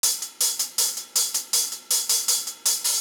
The Trailer HiHat Loop.wav